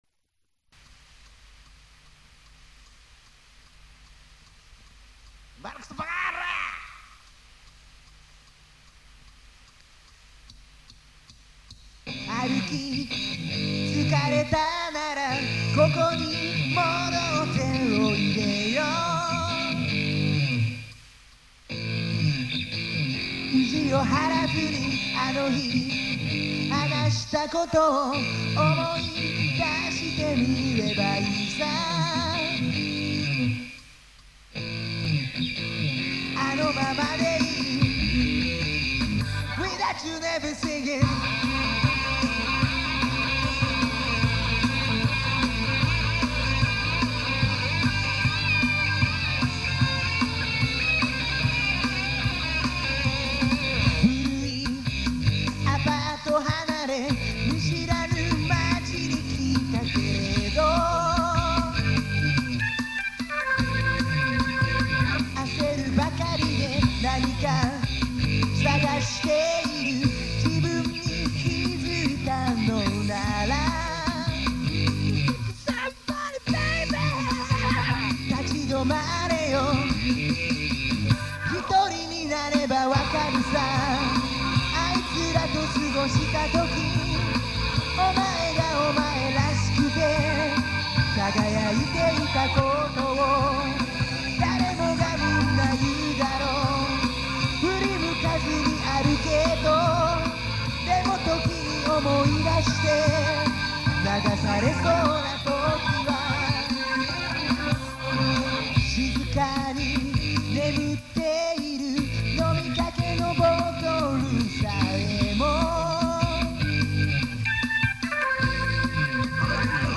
プレイヤーとしては、ドラムでも鍵盤でもなく、ギターでチャレンジ。 どこかのソロを弾いてます。
多重録音の手伝いというか、全部付き合いました。